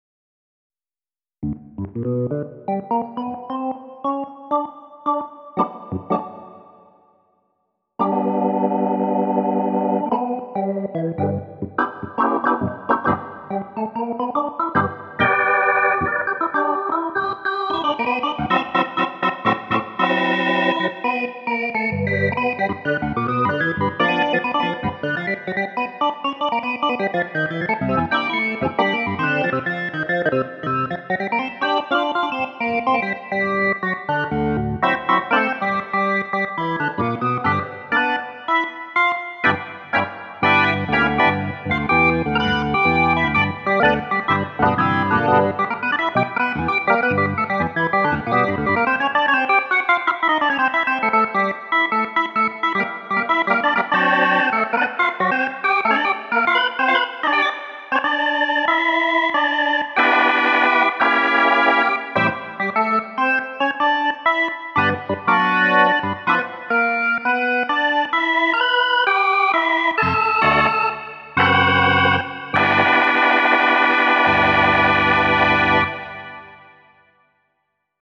Within a week I have made these sounds, and recordings of them, using 24 bit 44.1kHz TOS link:
hammond1.mp3